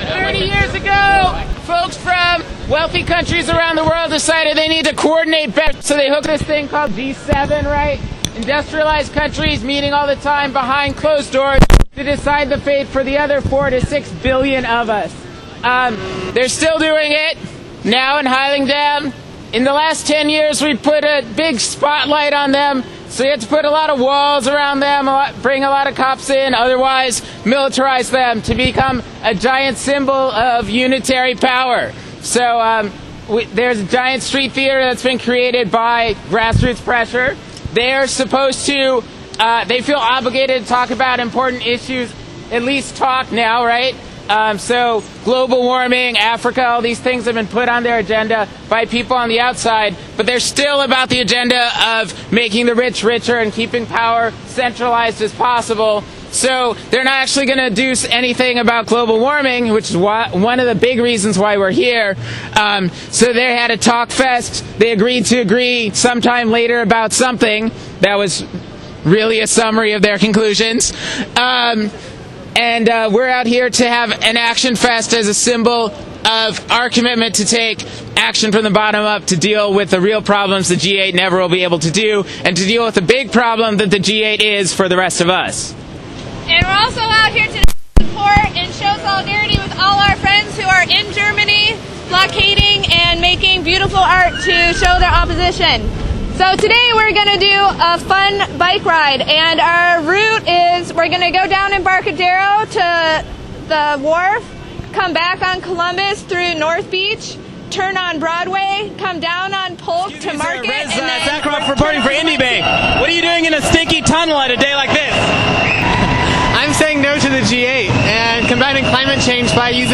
June 8 bike ride in Solidarity with G8 protests worldwide.